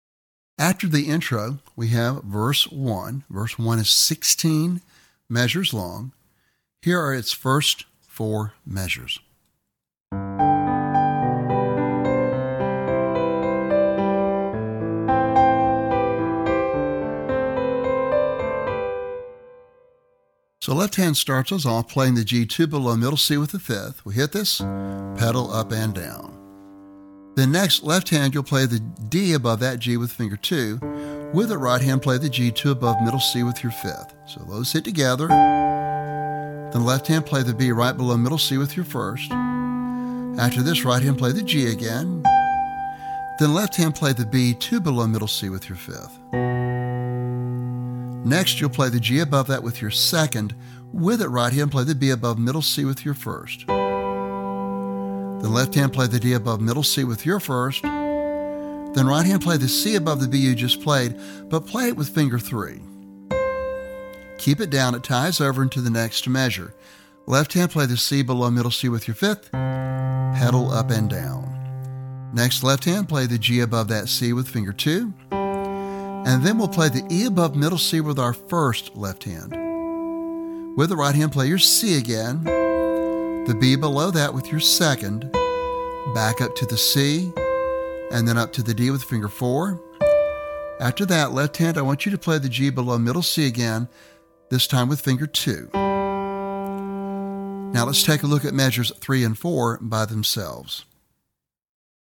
Lesson Sample  (Intermediate Level Solo)